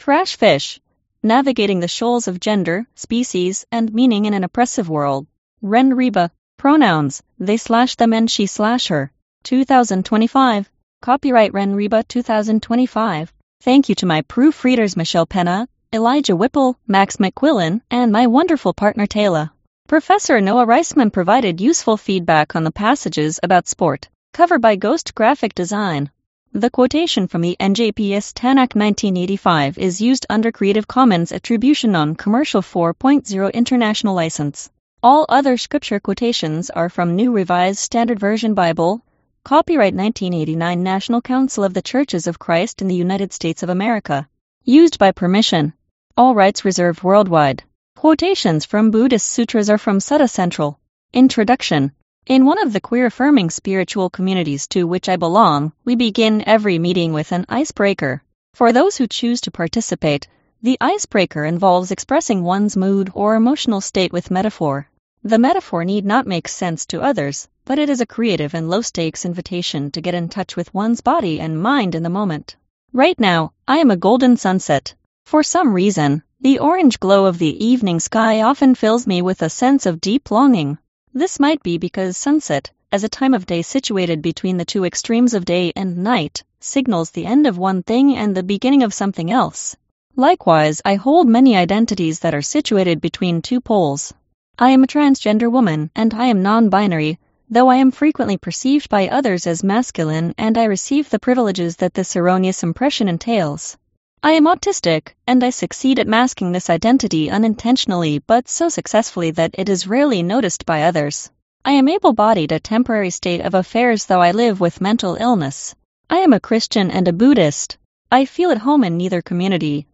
MP3 format (audiobook)
The MP3 audiobook file is produced using automated text-to-speech software ( Piper , with the voice Hfc Medium Female).